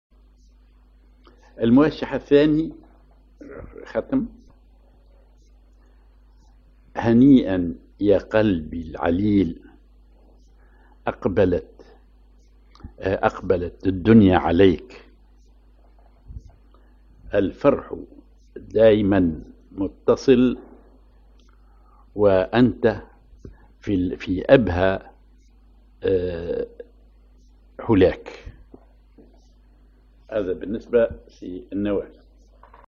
Maqam ar نواثر
Rhythm ar سماعي طائر
genre موشح